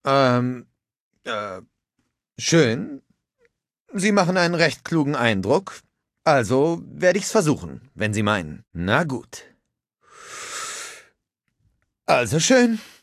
Charakter: Geisel des Predigers
Fallout 3: Audiodialoge